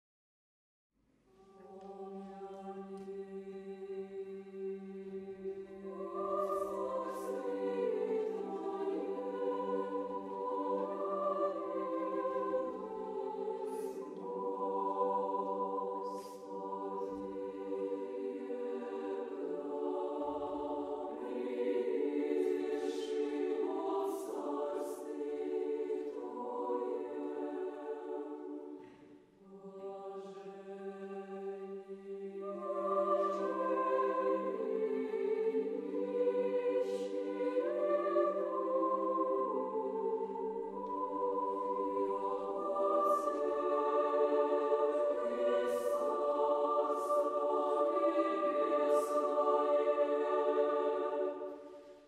Genre-Style-Forme : Sacré ; Hymne liturgique (orthodoxe) ; Orthodoxe ; Liturgie
Caractère de la pièce : solennel ; majestueux ; pieux
Type de choeur : SSAATTBB  (7 voix mixtes )
Tonalité : ré majeur
Réf. discographique : Internationaler Kammerchor Wettbewerb Marktoberdorf